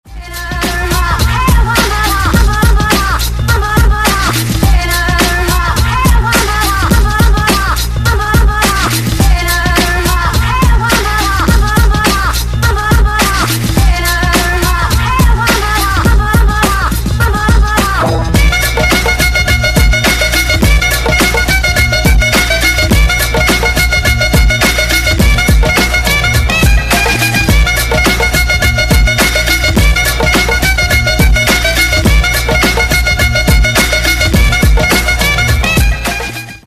Громкие Рингтоны С Басами
Танцевальные Рингтоны